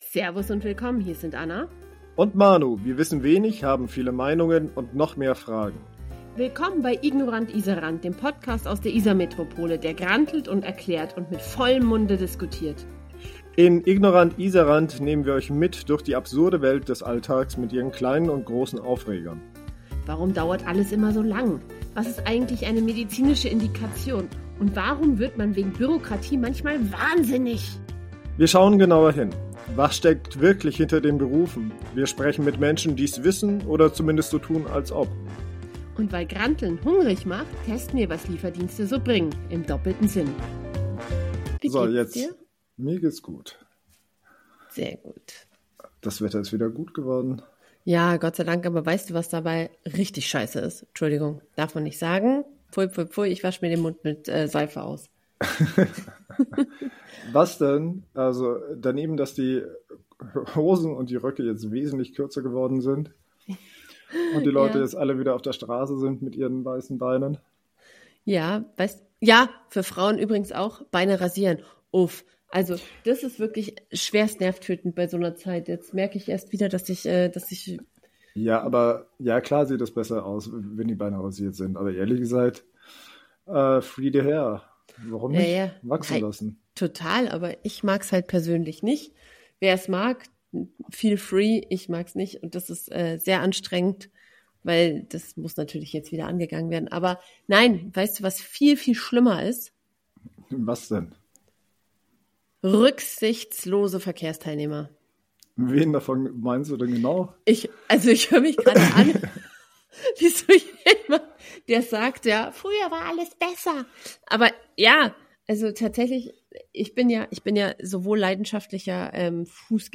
Und weil Wut hungrig macht, gibt’s afghanisches Essen direkt an den Podcast-Tisch.
Es wird geschimpft, bestellt und gebrüllt – der Bär hat wieder Puls.